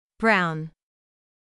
/aʊ/は、日本語で表現するなら「アーゥ」のような音になります。
強く長めの「ア」から、顎を閉じて弱く短い「ゥ」の音へと、流れるようにつなげて発音します。
brown [braʊn]